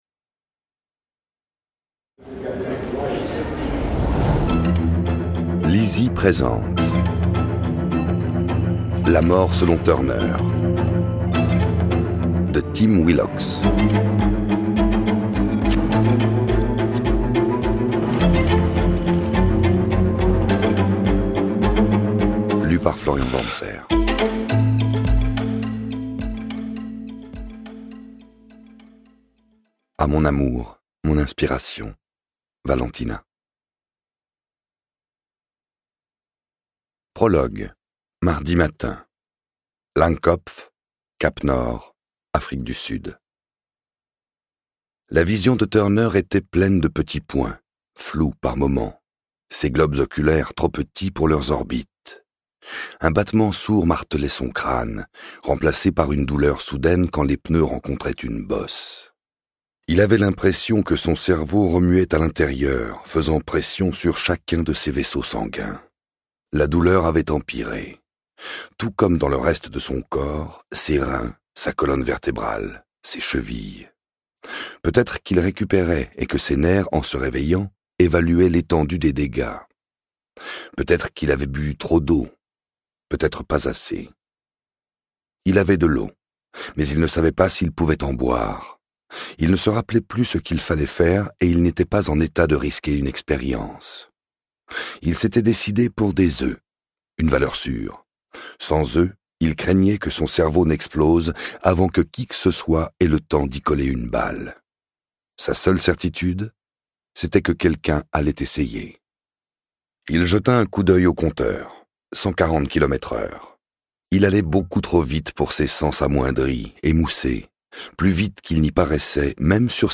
Click for an excerpt - La Mort selon Turner de Tim WILLOCKS